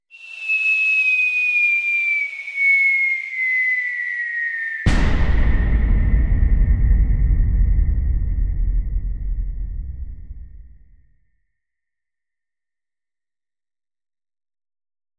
Звуки бомбёжек
Со взрывом